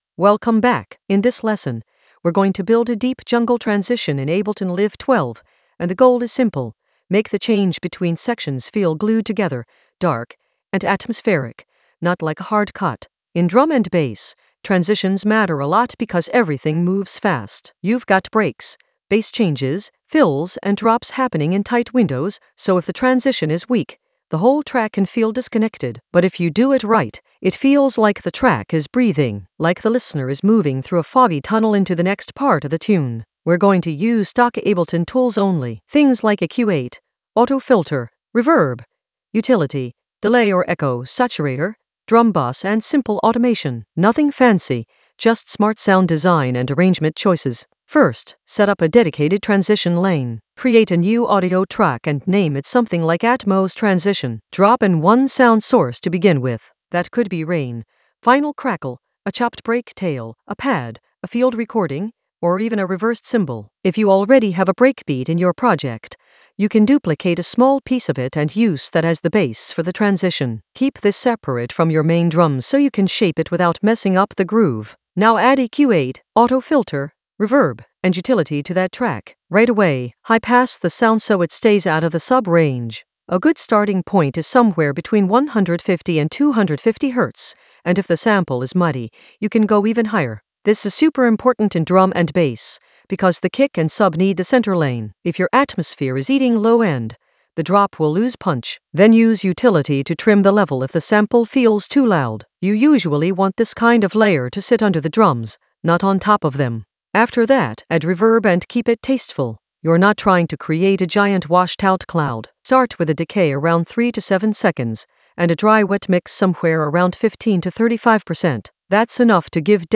An AI-generated beginner Ableton lesson focused on Glue a transition for deep jungle atmosphere in Ableton Live 12 in the Atmospheres area of drum and bass production.
Narrated lesson audio
The voice track includes the tutorial plus extra teacher commentary.